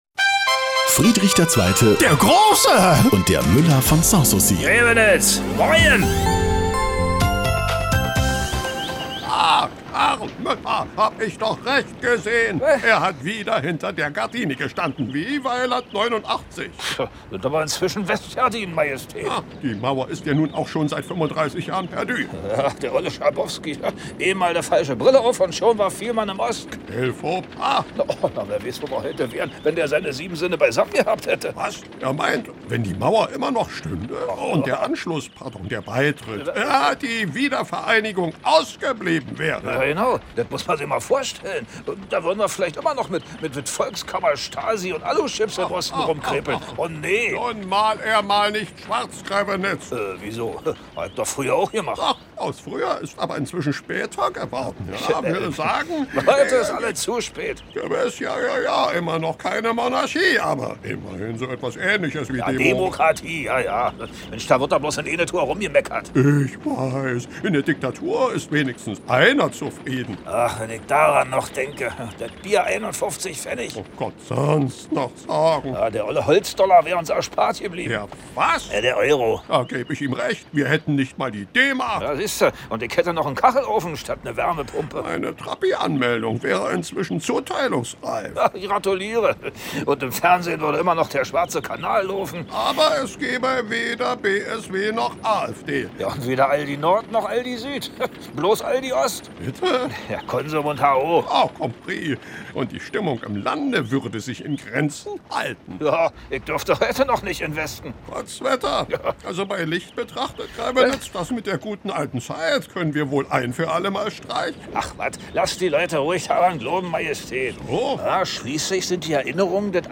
… continue reading 25 afleveringen # Komödie # Antenne Brandenburg, Rundfunk berlin-Brandenburg, Germany # Antenne Brandenburg # Rundfunk Berlin-brandenburg